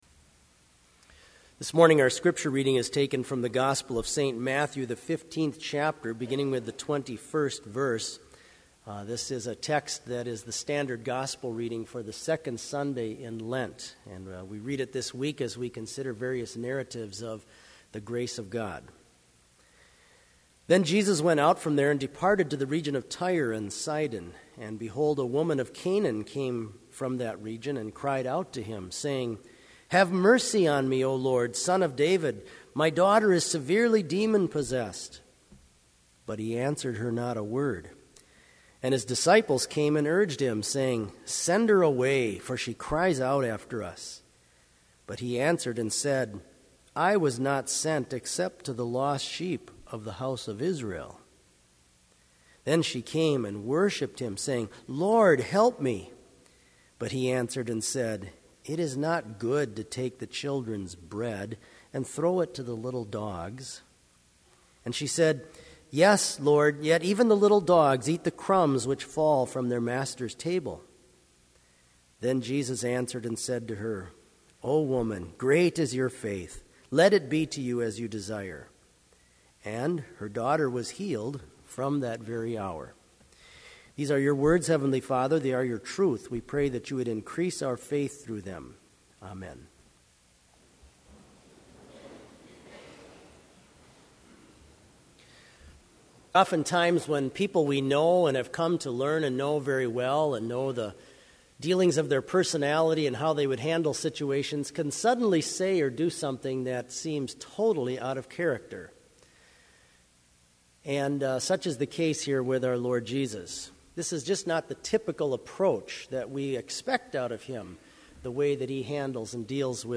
Bethany Lutheran College chapel service listing for February 2010, including names of the preacher and musicians participating in the worship services.